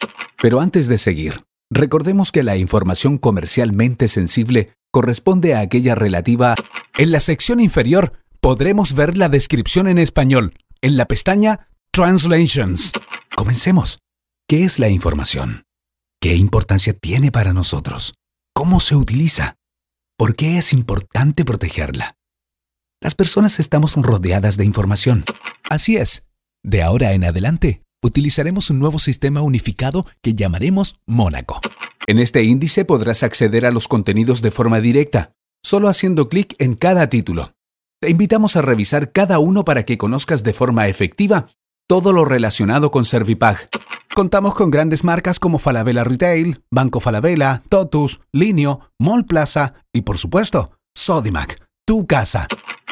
Male
Adult (30-50)
Natural Speak